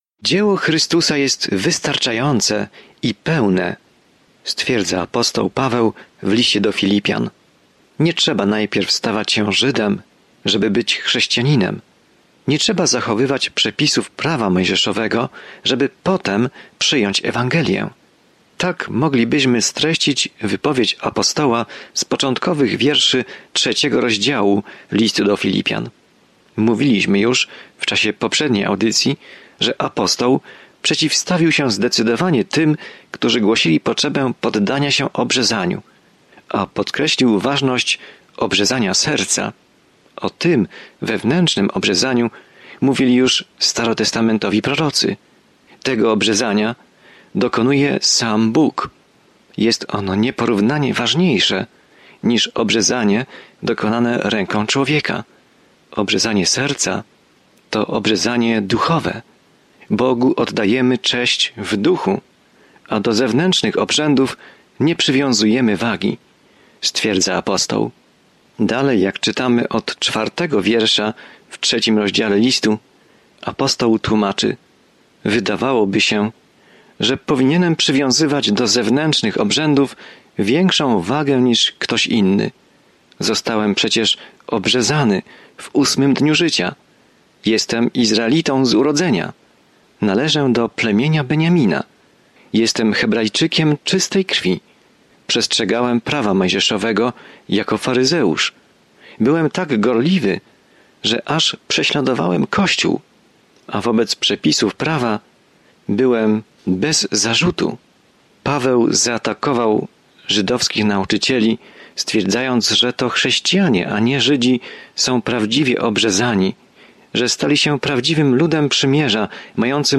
Pismo Święte Filipian 3:4-9 Dzień 10 Rozpocznij ten plan Dzień 12 O tym planie To podziękowanie skierowane do Filipian daje im radosną perspektywę na trudne czasy, w których się znajdują, i zachęca ich, aby pokornie przez nie przejść razem. Codziennie podróżuj przez List do Filipian, słuchając studium audio i czytając wybrane wersety słowa Bożego.